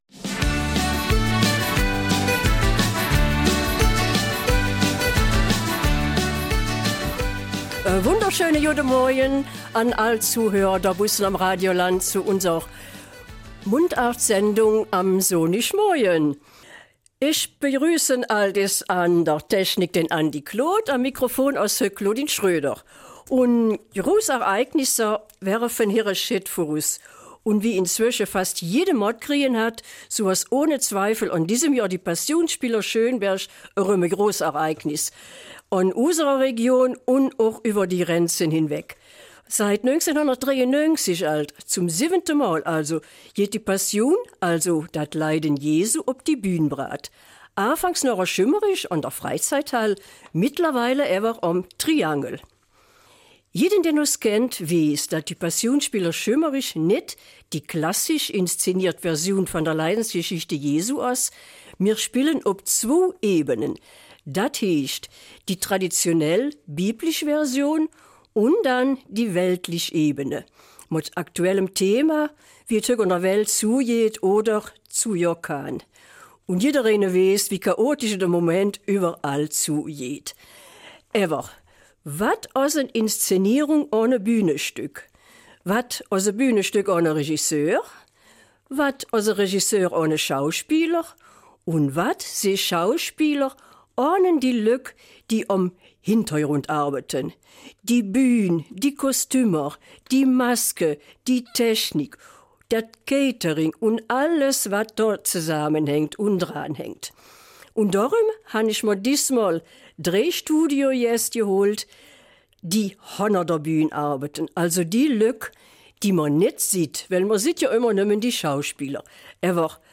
Eifeler Mundart - 2. Februar